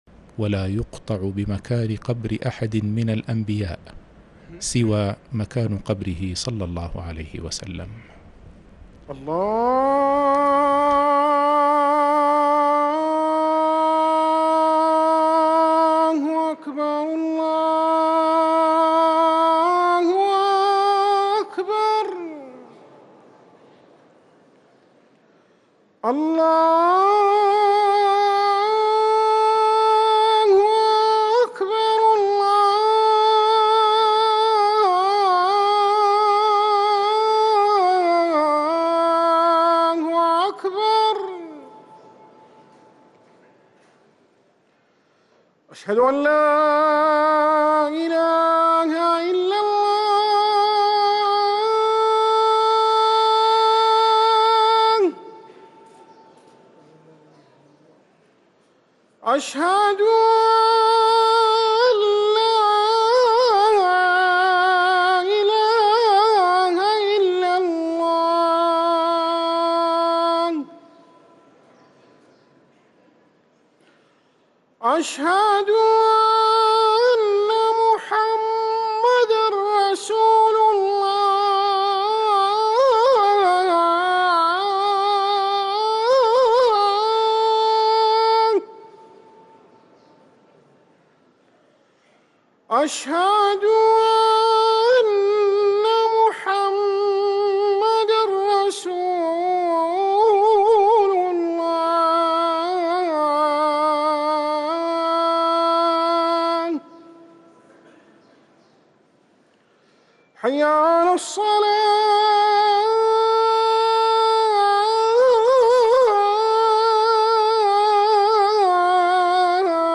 أذان المغرب
ركن الأذان